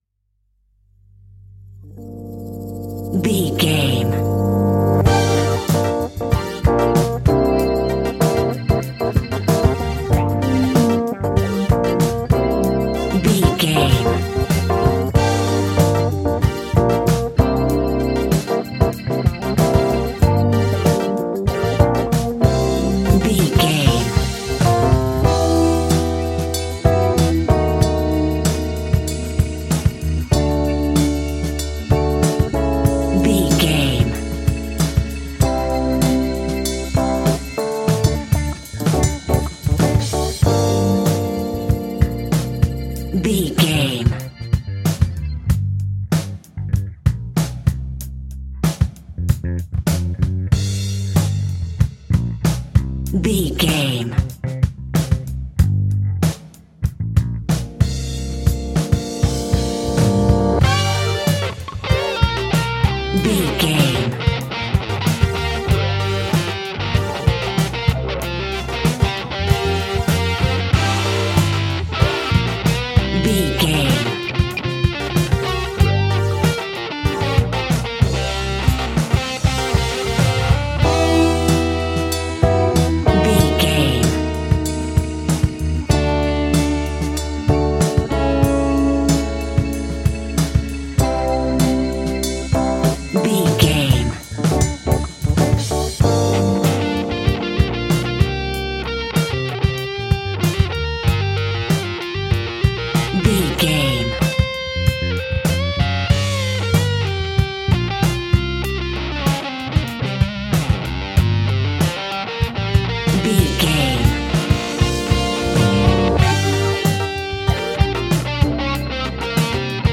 Aeolian/Minor
funky
uplifting
bass guitar
electric guitar
organ
drums
saxophone